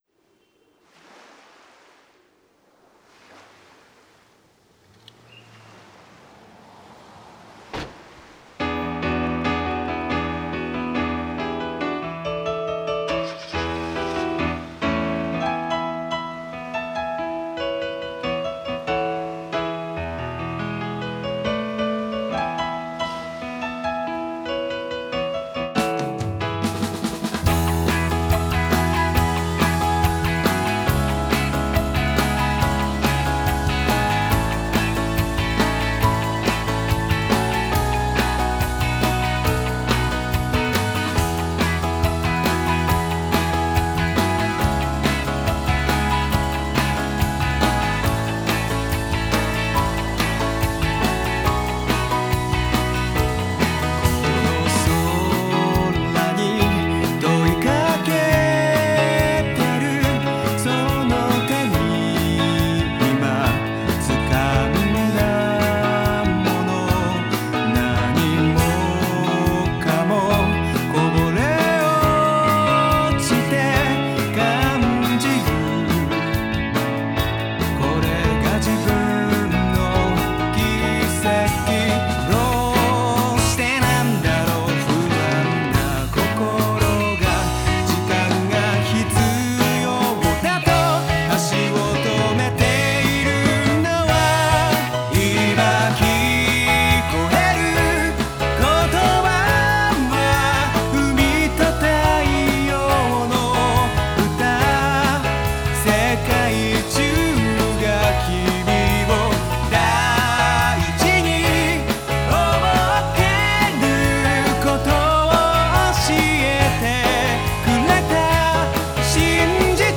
BEFORE MIX